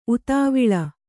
♪ utāviḷa